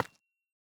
Minecraft Version Minecraft Version 21w07a Latest Release | Latest Snapshot 21w07a / assets / minecraft / sounds / block / calcite / place2.ogg Compare With Compare With Latest Release | Latest Snapshot